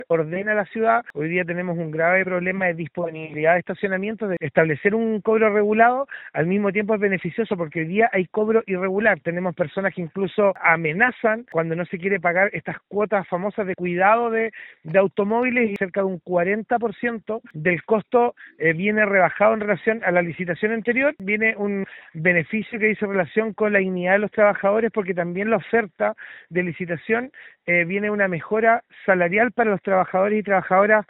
En conversación con Radio Bío Bío, el vice presidente de la comisión de Vivienda, Urbanismo, Tránsito y Transporte del Concejo Municipal, Lucio Sanhueza, detalló que cuando comience a funcionar la nueva empresa a cargo de los parquímetros, habrá más orden en el centro y además la tarifa será menor a la que había en la antigua licitación.